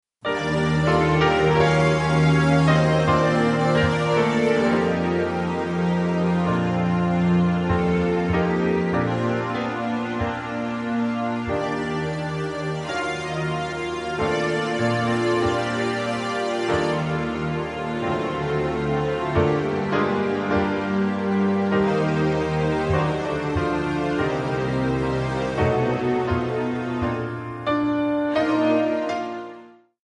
Bb
MPEG 1 Layer 3 (Stereo)
Backing track Karaoke
Oldies, Musical/Film/TV, 1950s